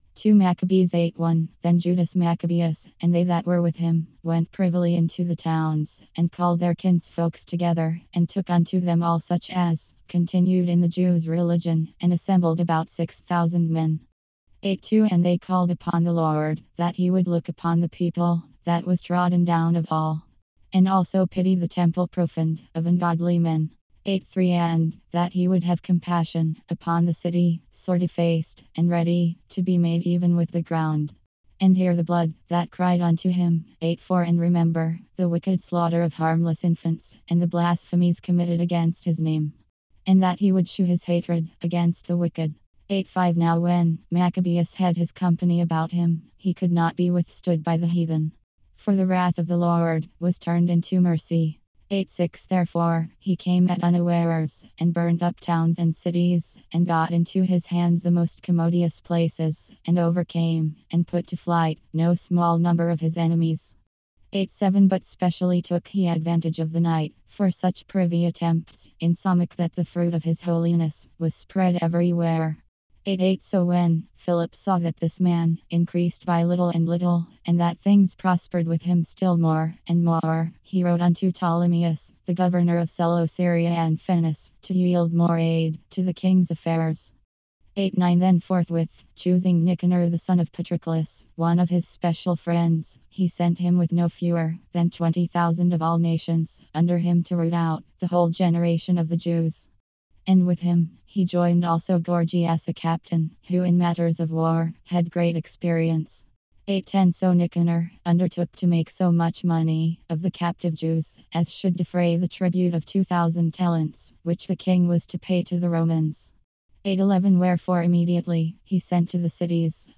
KJV Spoken Word Index